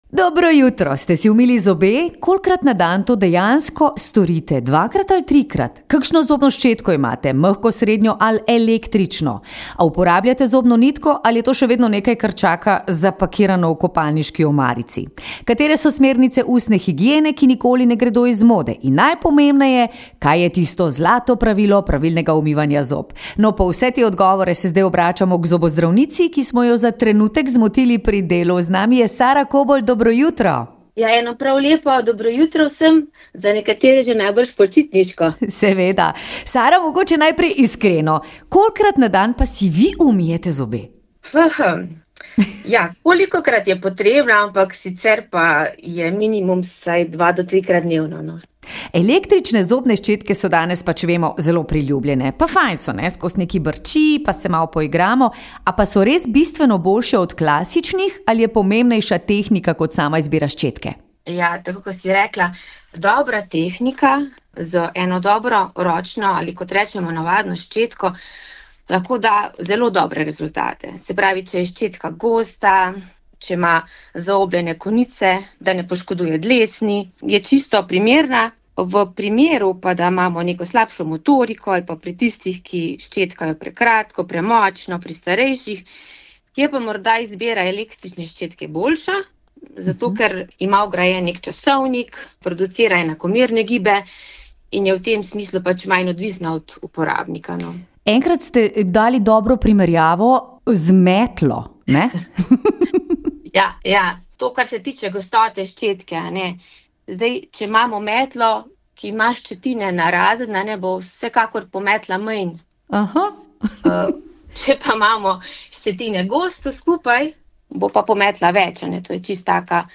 V jutranjem programu smo tokrat odprli temo, ki se zdi samoumevna, a skriva veliko vprašanj – ustno higieno.